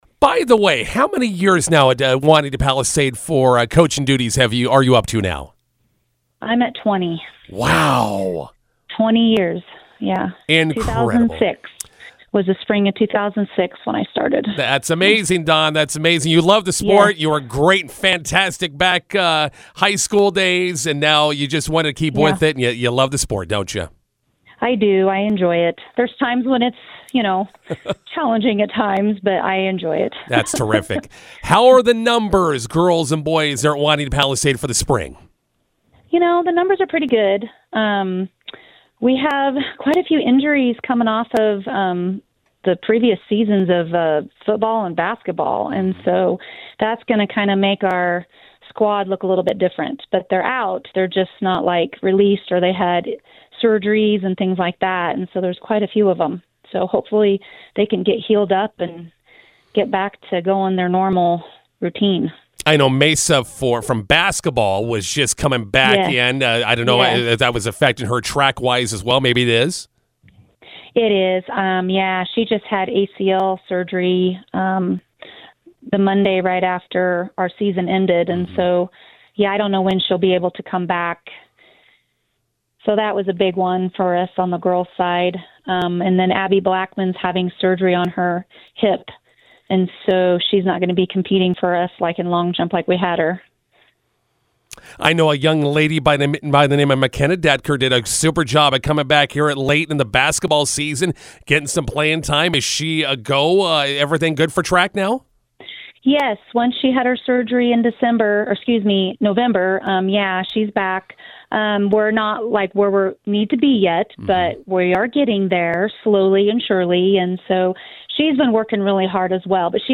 INTERVIEW: Wauneta-Palisade Track and Field teams open season Thursday at Perkins County.